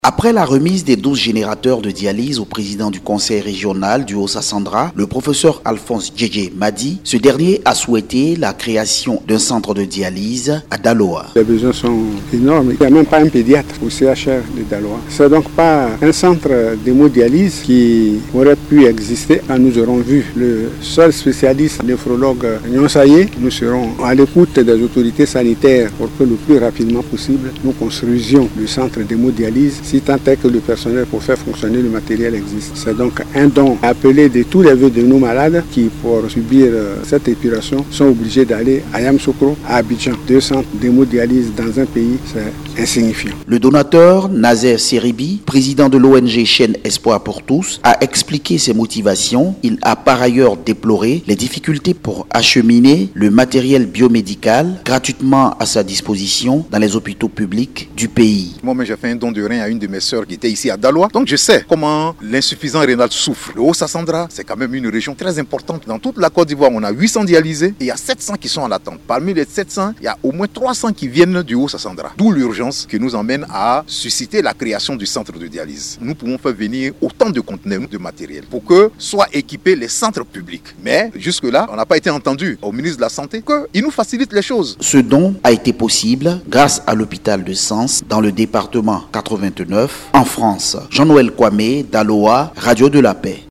Les deux partenaires, le conseil régional et le donateur ont appelé à l’amélioration du système de santé dans le haut-Sassandra et souhaité la création urgente d’un centre d’hémodialyse à Daloa ; pour soulager les nombreux patients en attente d’une hémodialyse et désengorger les centres d’hémodialyses d’Abidjan et de Yamoussoukro. Compte rendu